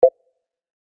Chat_Received.mp3